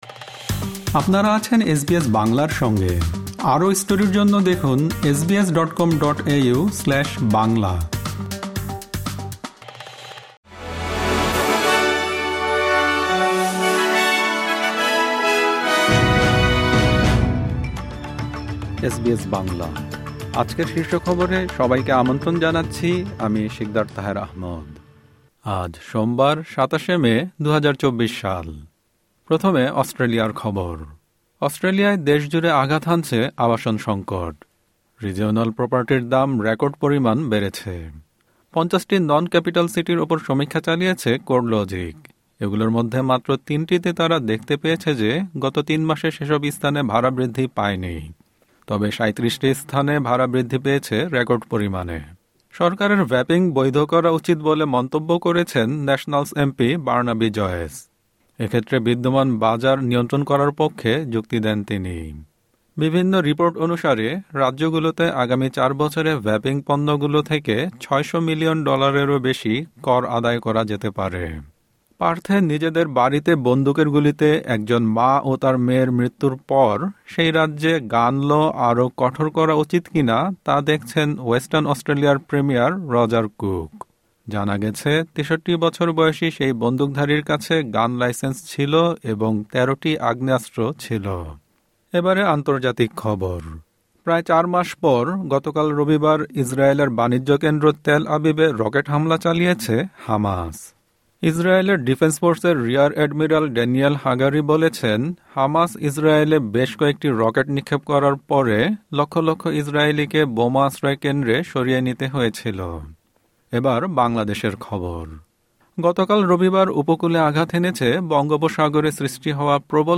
আজকের শীর্ষ খবর: গতকাল রবিবার বাংলাদেশের উপকূলে আঘাত হেনেছে বঙ্গোপসাগরে সৃষ্টি হওয়া প্রবল ঘূর্ণিঝড় রিমাল।